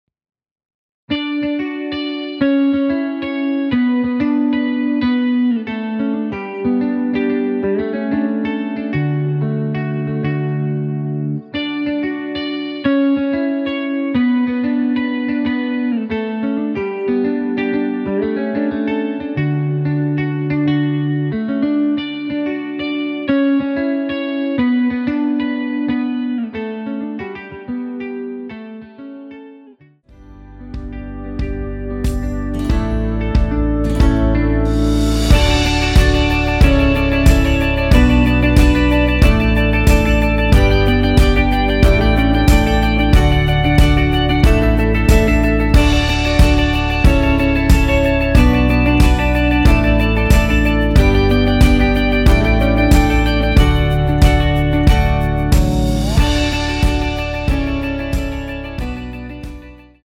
원키에서(+5)올린 MR입니다.
앞부분30초, 뒷부분30초씩 편집해서 올려 드리고 있습니다.